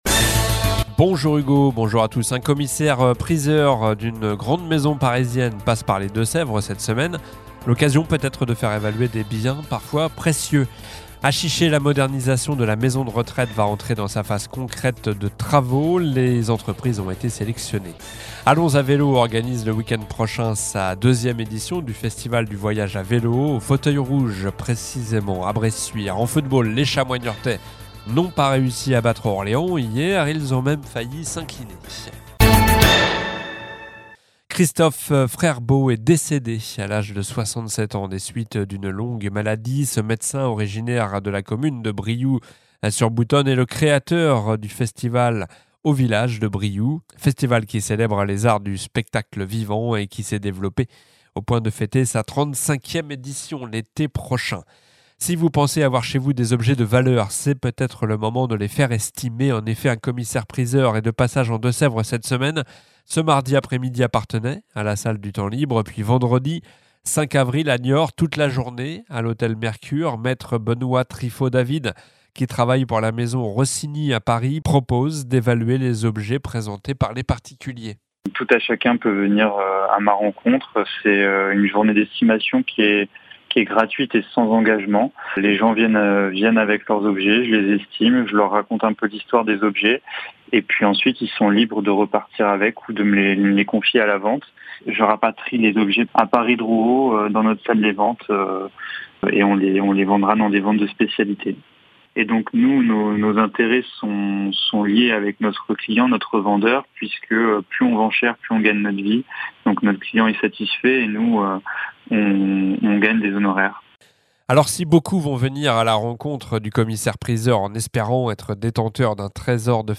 Journal du mardi 2 avril (midi)